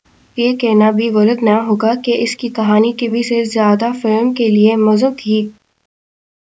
Spoofed_TTS/Speaker_12/202.wav · CSALT/deepfake_detection_dataset_urdu at main